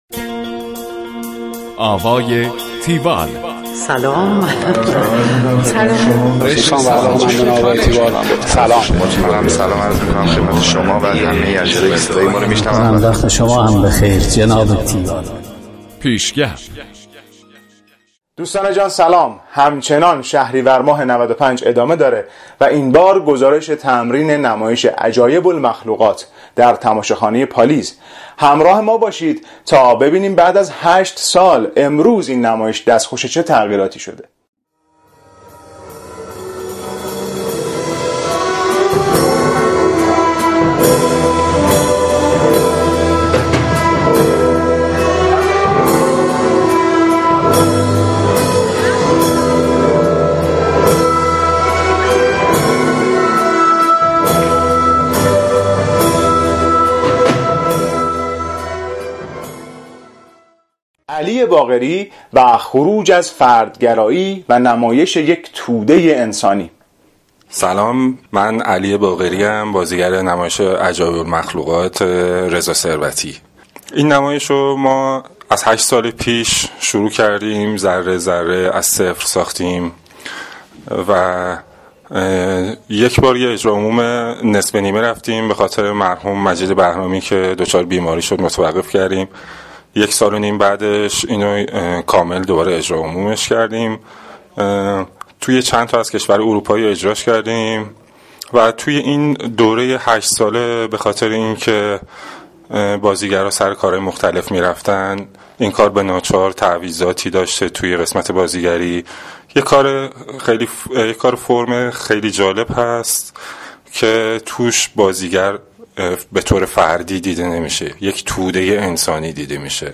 گزارش آوای تیوال از نمایش عجایب المخلوقات